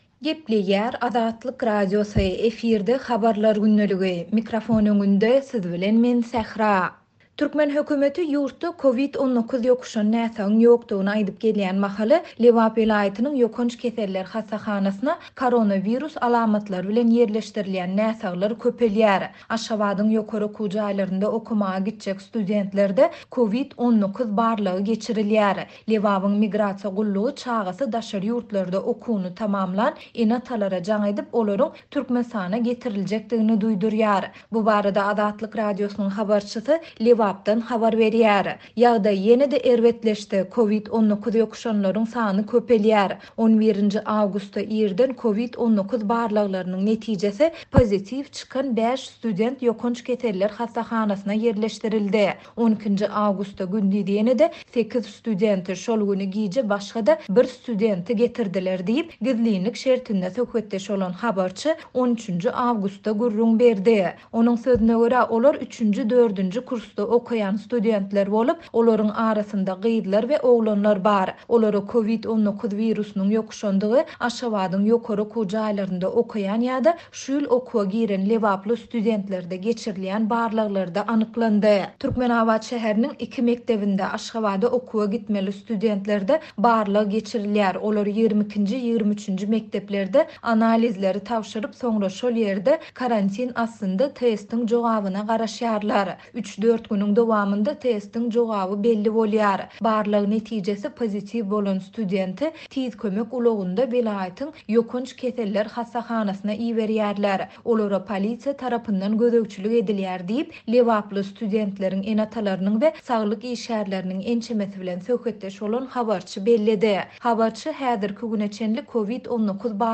Bu barada Azatlyk Radiosynyň habarçysy Lebapdan habar berýär.